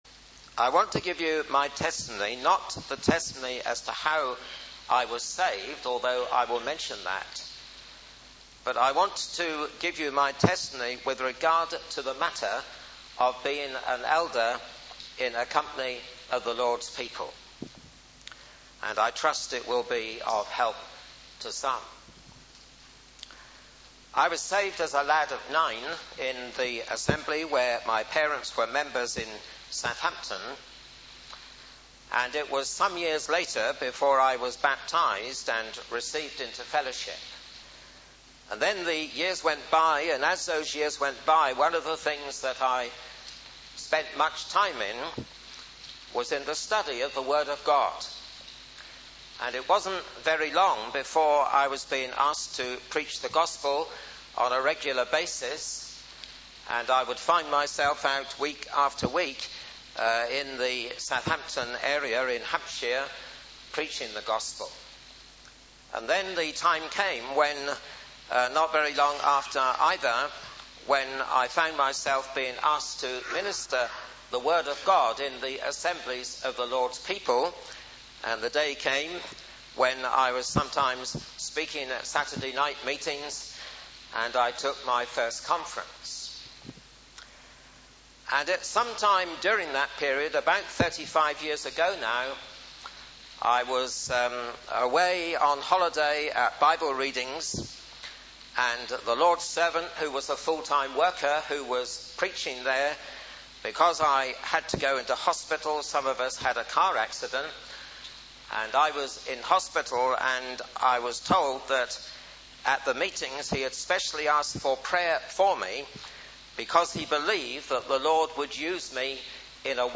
A vital, warm and discerning message from an experienced elder (2007)